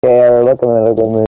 failVoice.mp3